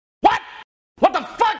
JonTron (WTF) Meme Effect sound effects free download